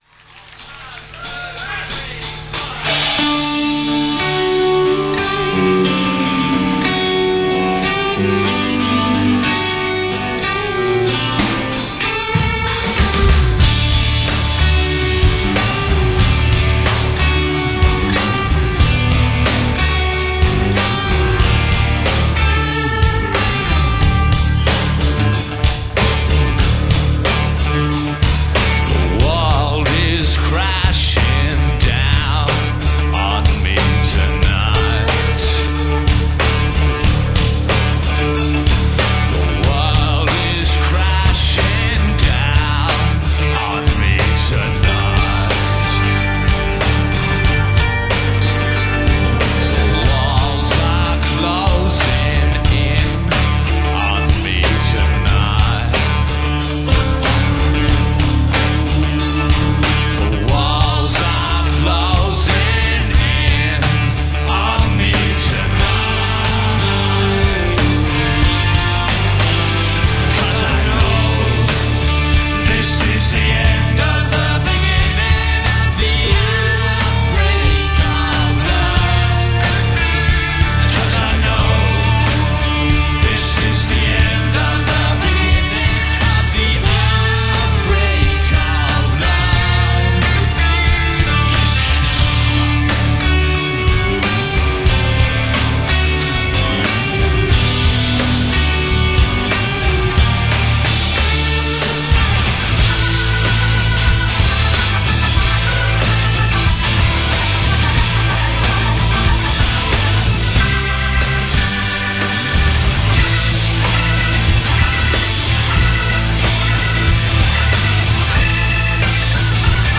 Australian rock band
vocals, harmonica
drums
guitar, keyboard
bass guitar